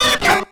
Cri de Coxyclaque dans Pokémon X et Y.